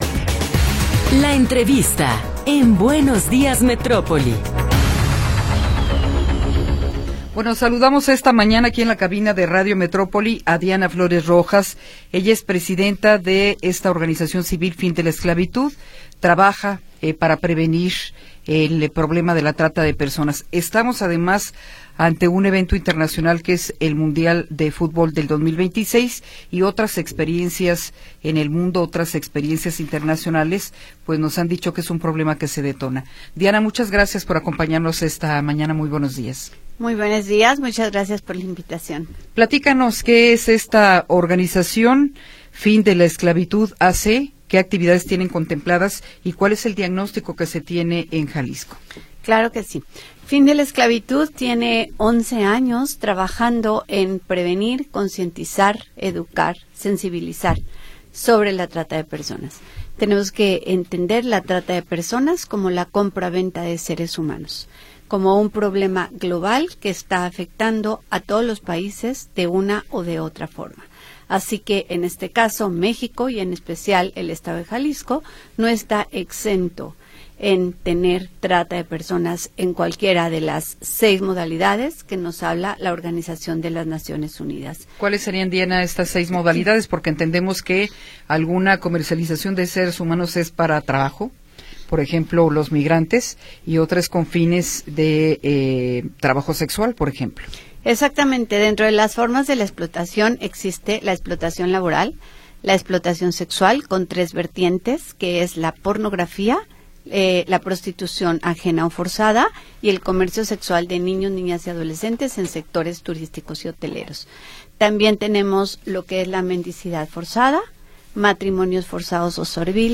Entrevista
Entrevistas